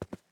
Player Character SFX